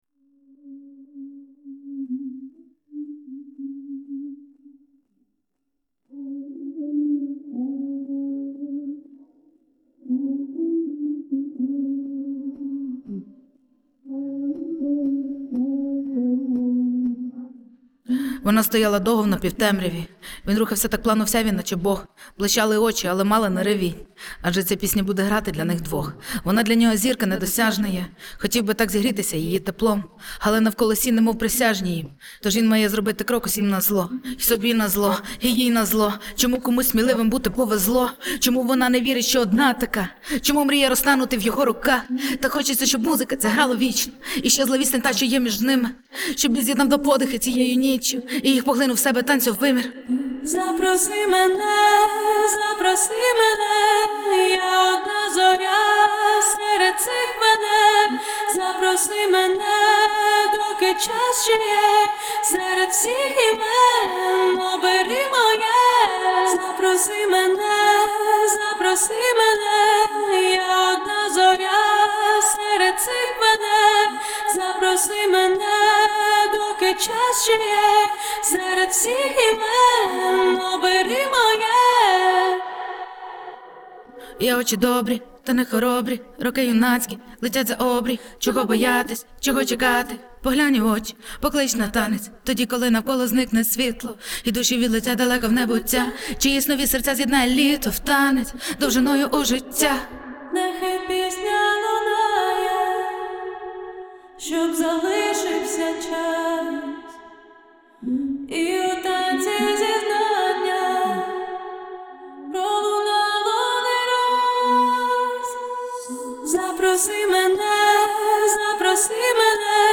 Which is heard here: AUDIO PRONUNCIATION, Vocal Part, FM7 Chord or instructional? Vocal Part